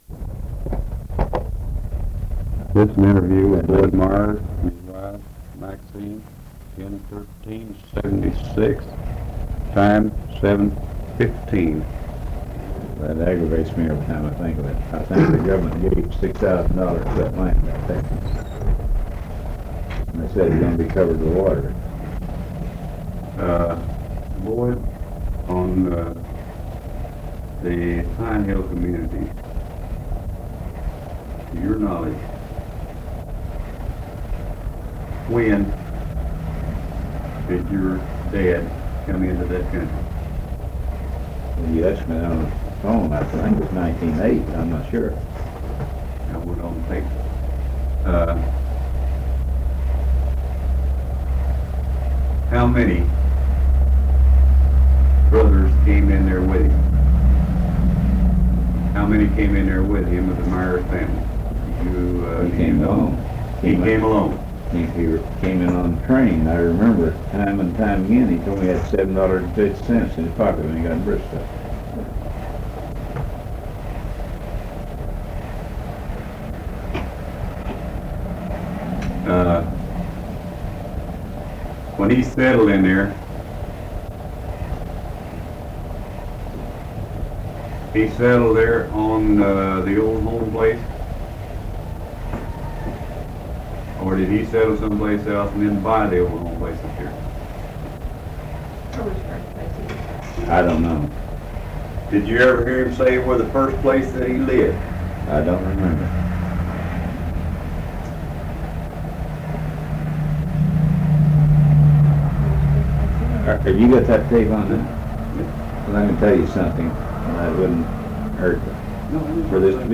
Bristow Historical Society - Oral History Archive | Pinehill Histories